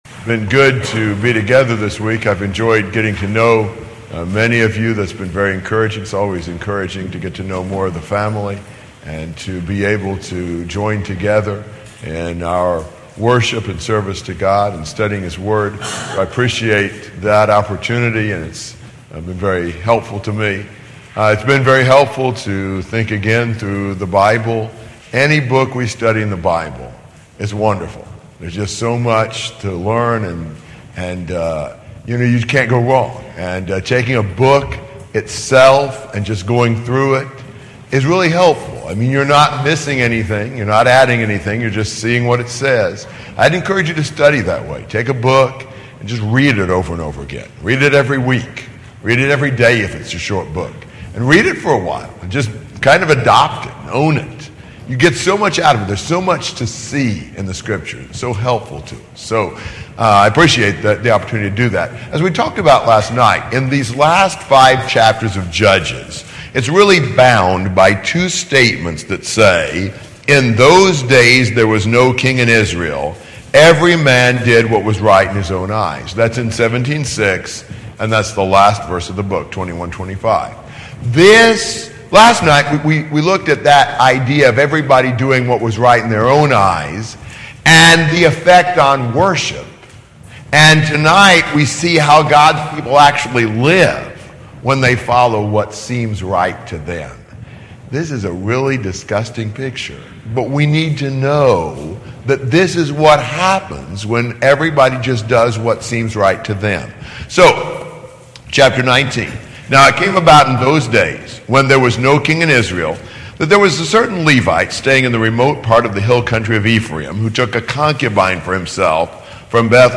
Series: Gettysburg 2015 Gospel Meeting